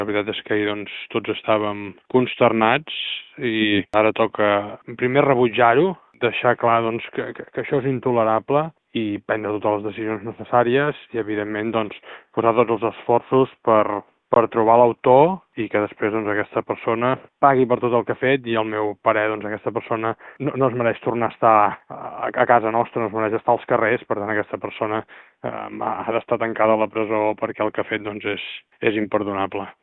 En declaracions a Ràdio Calella TV, Marc Buch ha posat l’accent en destinar tots els recursos per localitzar el presumpte autor i que respongui davant la justícia.